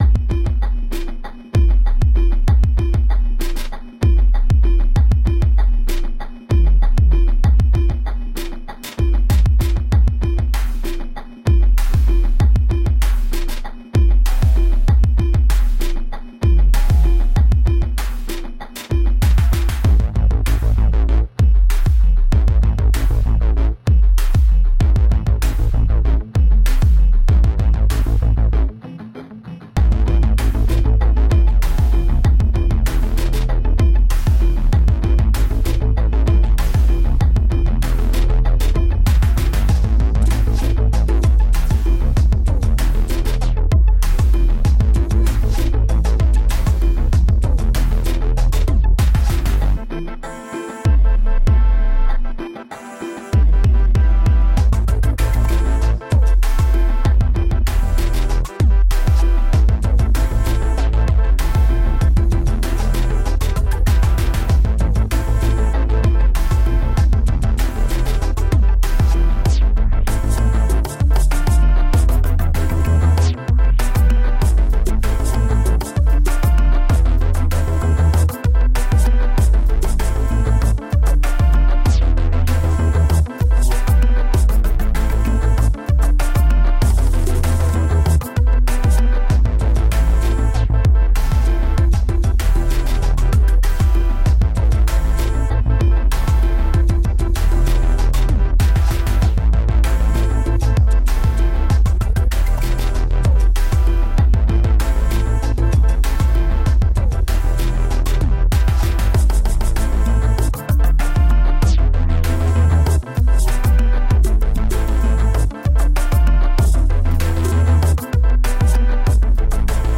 Urban electronic music.
electronic cumbia genre
the sound of cumbia with electronic music.
Tagged as: Electronica, Experimental, World Electronic